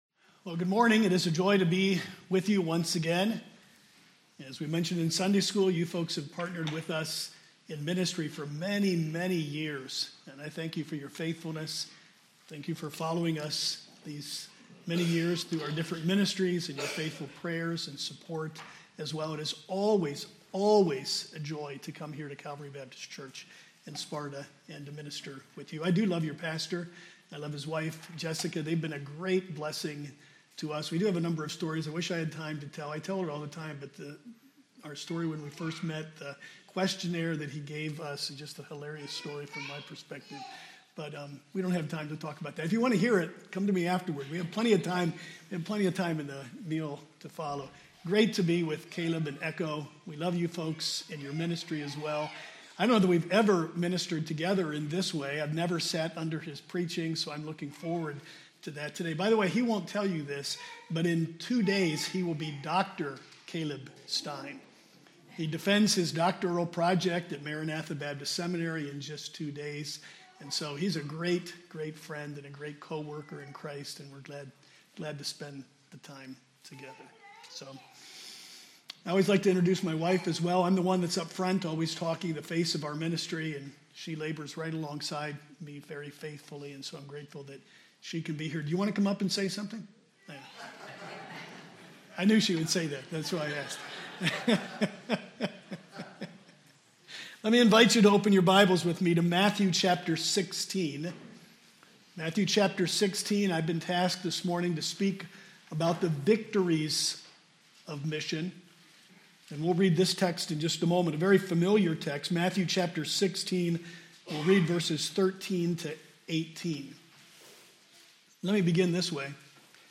Series: Missions and the Local Church Service Type: Sunday Morning Service